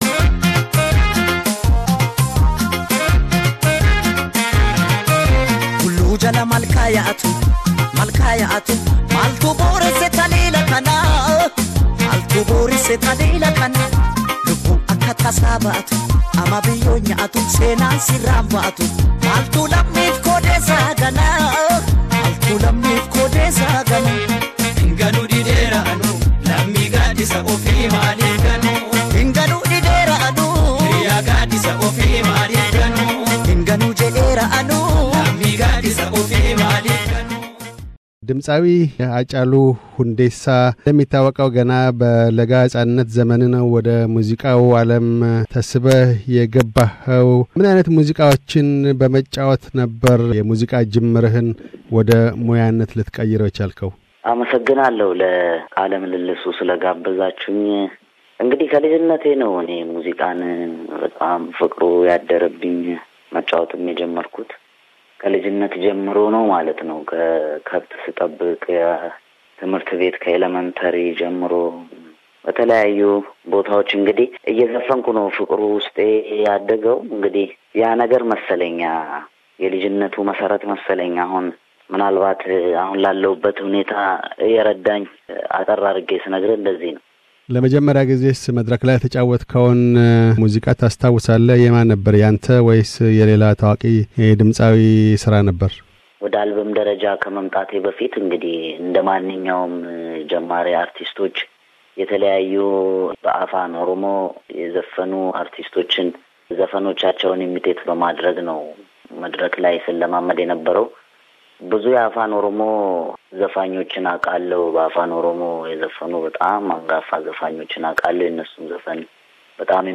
ድምፃዊ ሓጫሉ ሁንዴሳ ትናንት ሰኞ ምሽት ላይ አዲስ አበባ በገላን ኮንዶምኒየም አካባቢ በጥይት ተመትቶ ሕይወቱ አልፋለች። ጠቅላይ ሚኒስትር ዐቢይ አሕመድም "ውድ ሕይወት" አጥተናል ሲሉ የተሰማቸውን ኃዘን ገልጠዋል። ሕልፈተ ሕይወቱን ምክንያት በማድረግ በ2015 ከድምፃዊ ሃጫሉ ሁንዴሳ ጋር ስለ ሙዚቃ ሕይወቱ ያደረግነውን ቃለ ምልልስ ዳግም አቅርበናል።